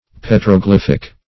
Search Result for " petroglyphic" : The Collaborative International Dictionary of English v.0.48: Petroglyphic \Pet`ro*glyph"ic\, a. Of or pertaining to petroglyphs or petroglyphy.